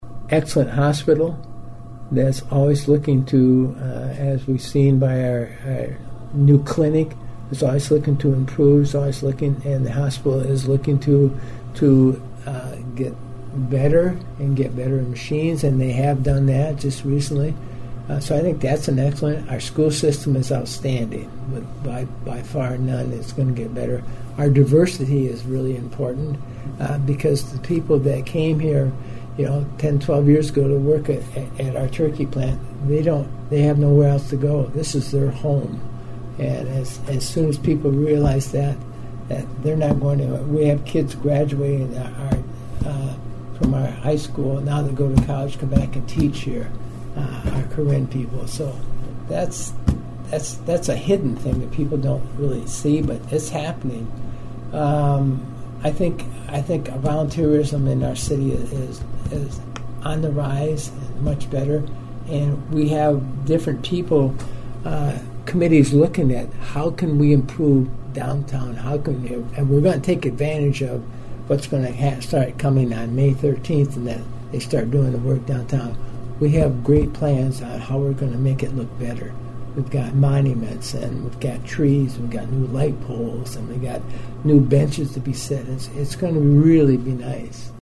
I spoke with Mayor Harrington about the future of the city of Huron including its obstacles to improvement.
Mayor talks about the positives in the city of Huron.